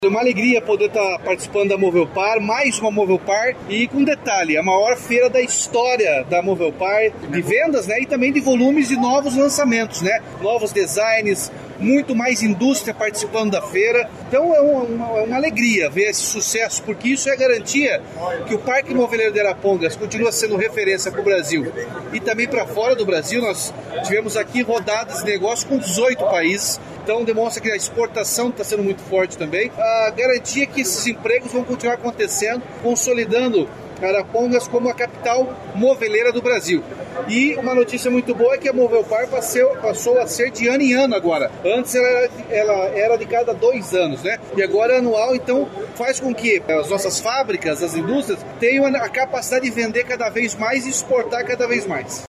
Sonora do governador Ratinho Junior sobre a Movelpar Home Show 2024